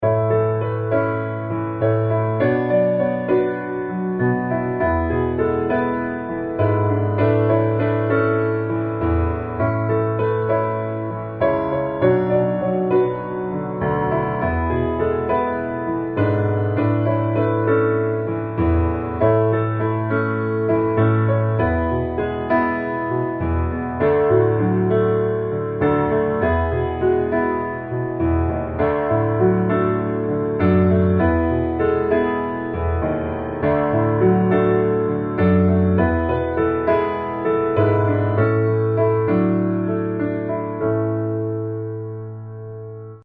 A Majeur